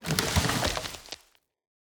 tree-leaves-3.ogg